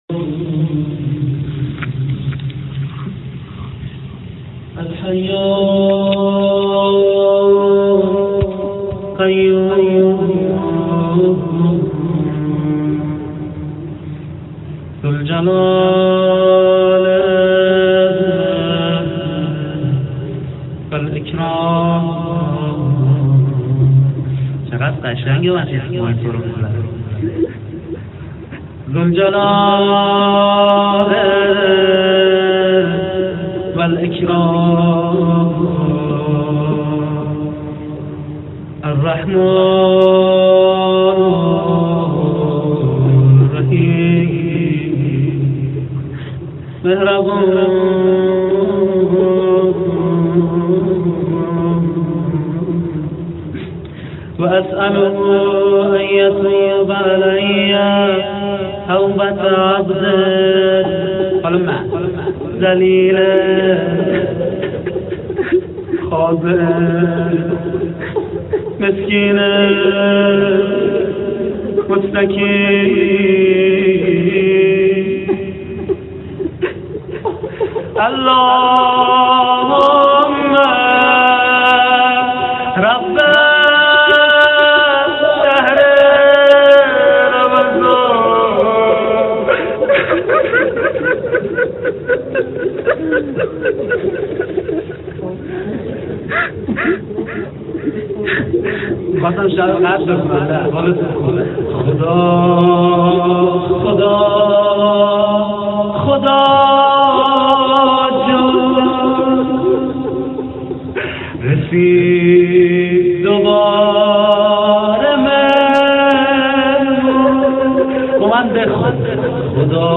قسمت اول روضه.mp3
قسمت-اول-روضه.mp3